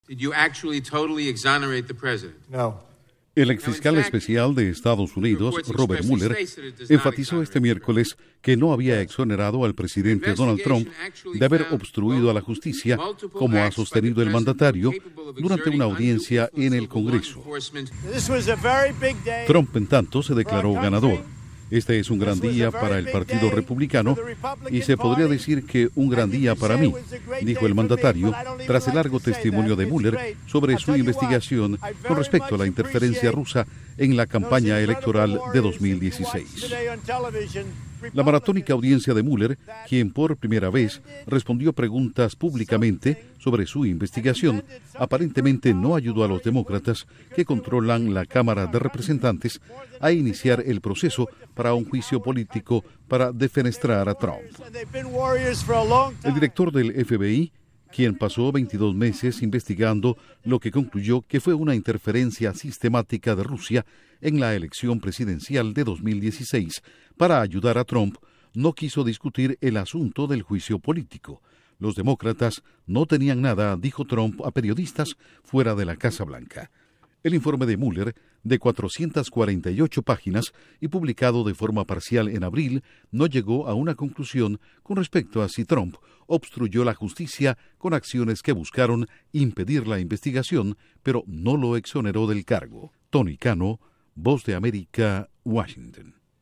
Duración: 1:36 1 audio de Robert Mueller/Ex fiscal especial de EE.UU. 1 audio de Donald Trump/Presidente de EE.UU.